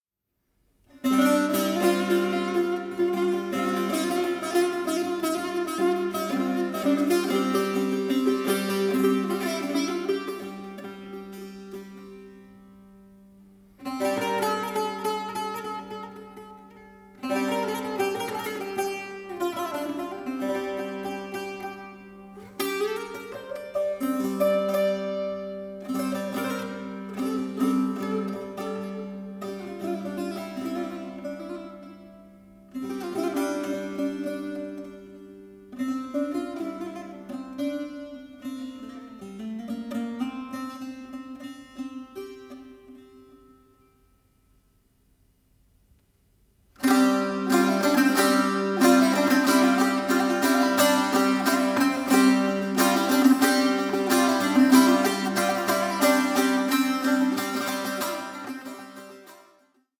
bağlama